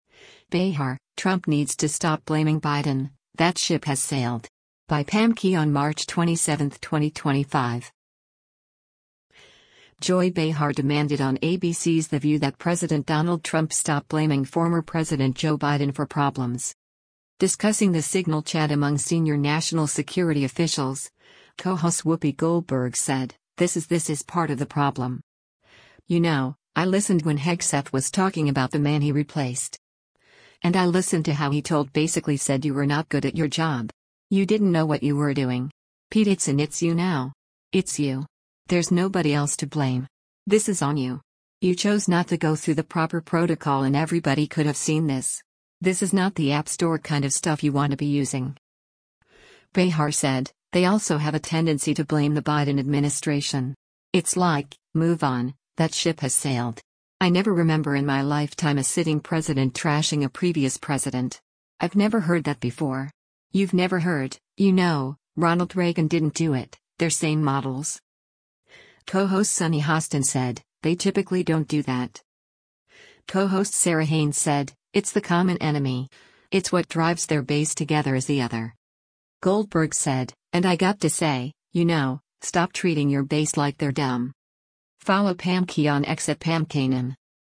Joy Behar demanded on ABC’s “The View” that President Donald Trump stop blaming former President Joe Biden for problems.
Co-host Sunny Hostin said, “They typically don’t do that.”